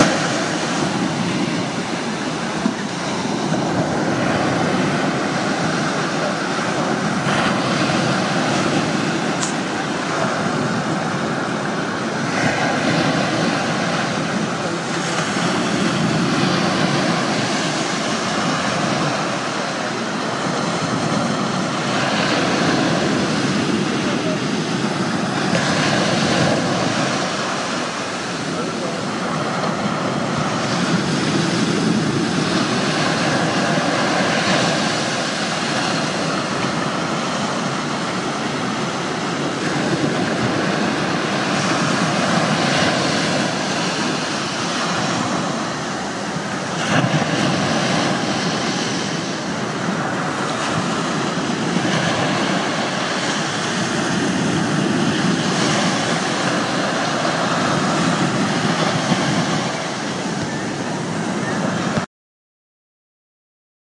环境声音 " 海岸边平静的海浪
描述：在Moto G手机中录制立体声。
标签： 海洋 R10 里约热内卢 巴西
声道立体声